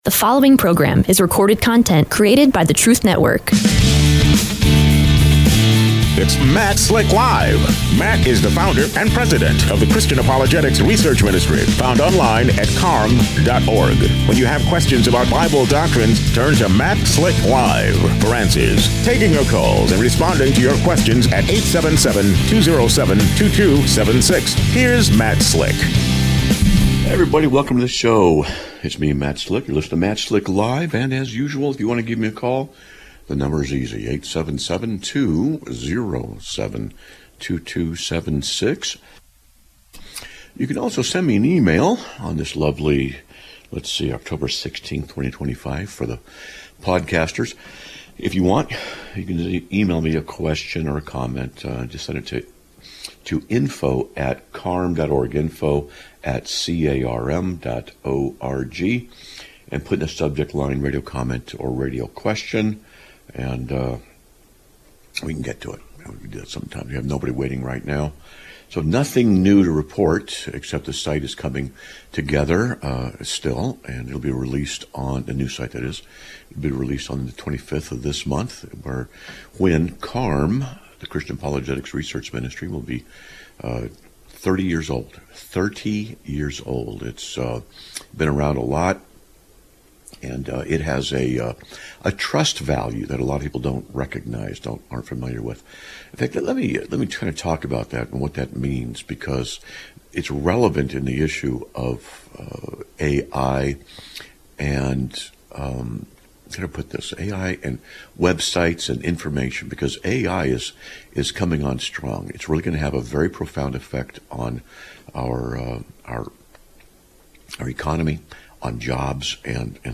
Live Broadcast of 10/16/2025) is a production of the Christian Apologetics Research Ministry (CARM).